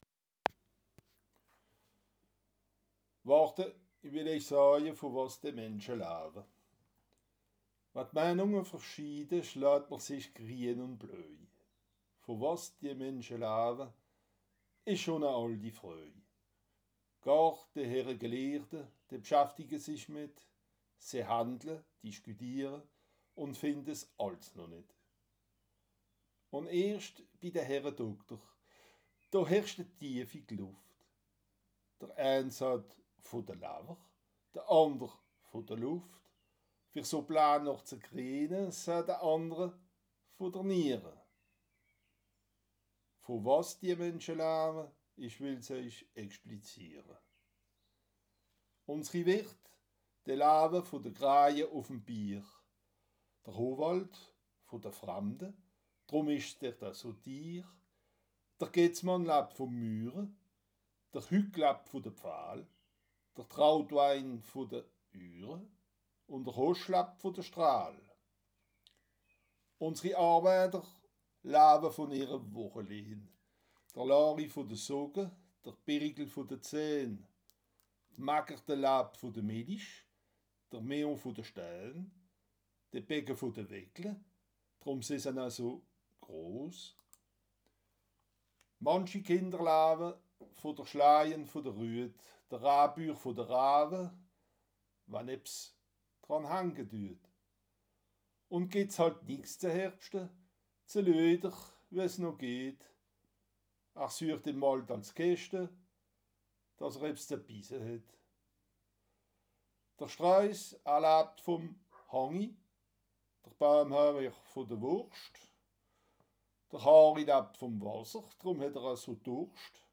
Le parler de BARR (dialecte alsacien)